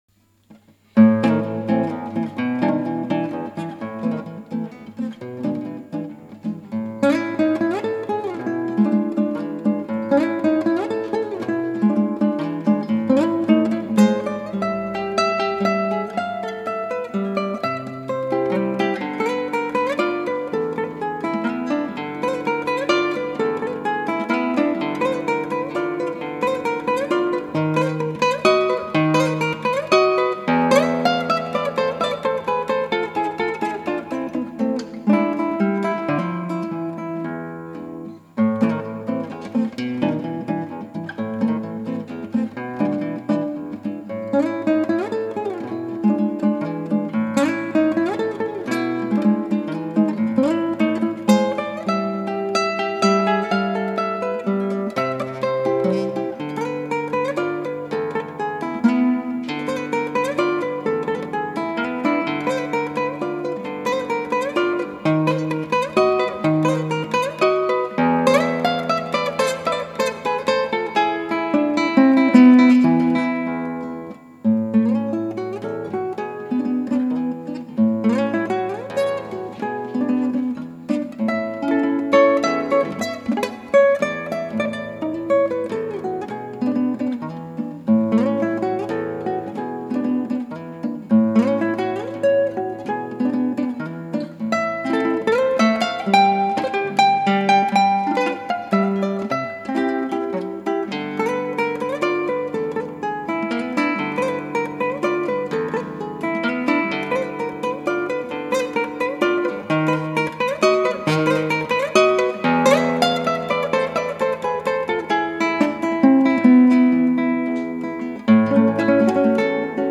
クラシックギター　ストリーミング　コンサート
どうも按配悪いです。
なにをやりたいのかワカラン演奏になっています。